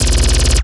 Added more sound effects.
LASRGun_Alien Handgun Burst_05_SFRMS_SCIWPNS.wav